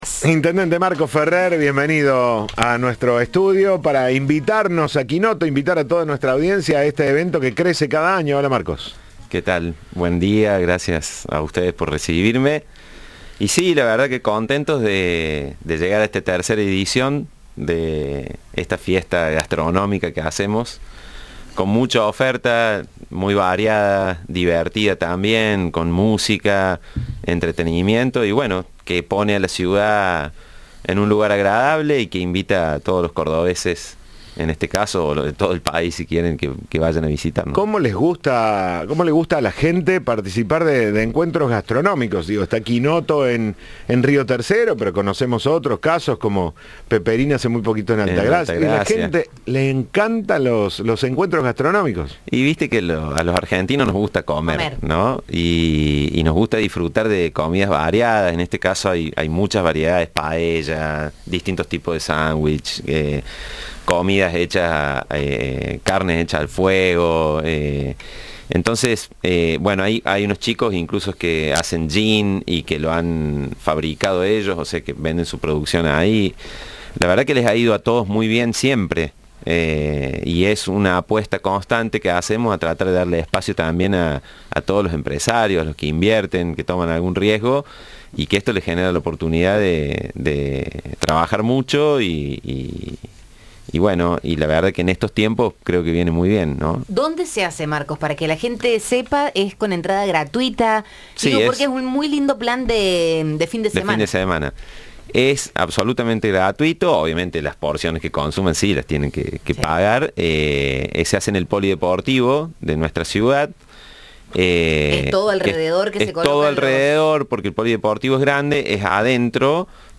Audio. El intendente de Río Tercero invitó a la fiesta gastronómica de cocina y arte